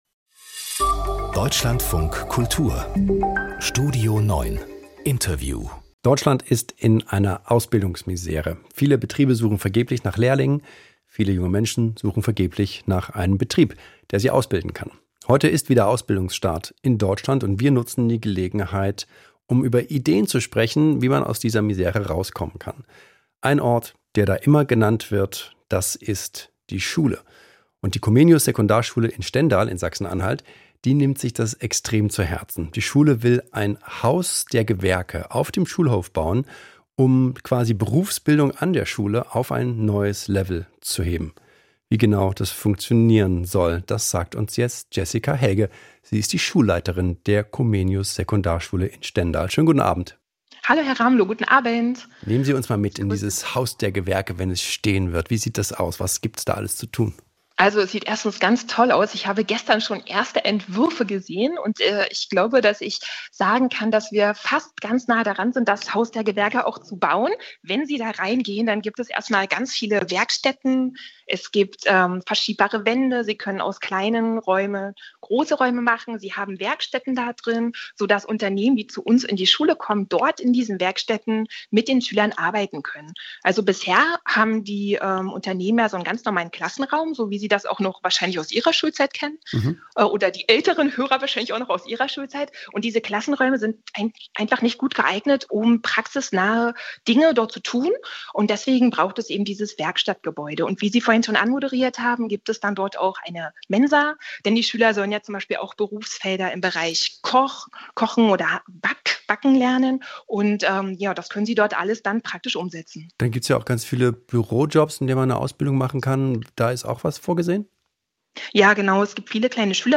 Interview: Traumjob finden. Der visionäre Plan einer Stendaler Schule (DLF-Kultur, 01.08,25)